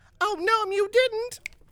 Recorded live at Adepticon 2019.